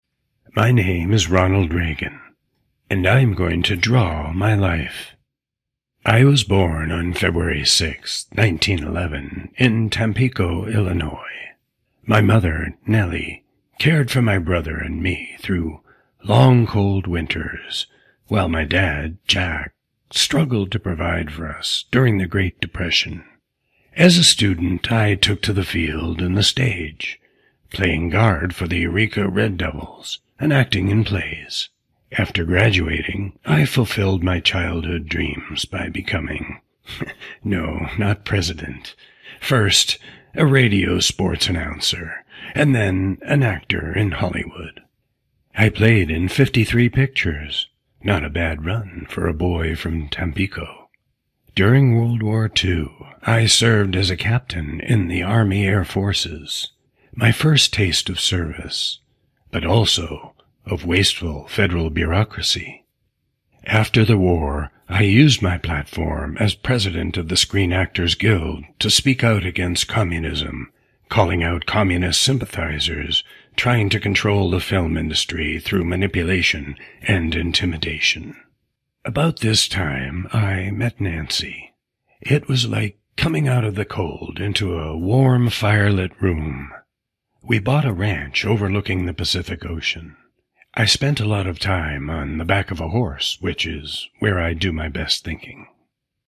Ronald Reagan: My Story (My voice as Reagan)
Depending on the projects needs, I will record using a Sennheiser MKH416 or a TLM 103 mic in a sound studio with Avid Pro Tools Studio Edition.